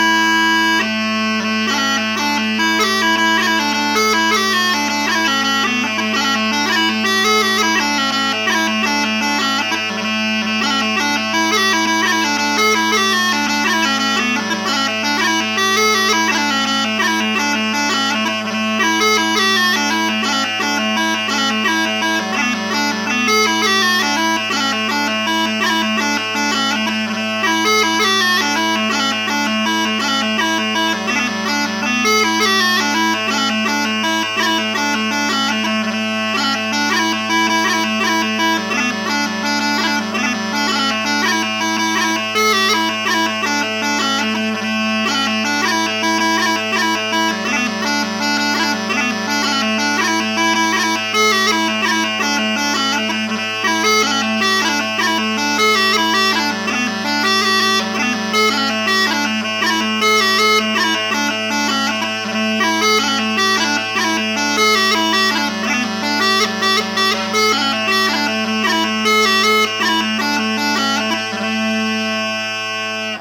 A tune I wrote with smallpipes in mind.
Jig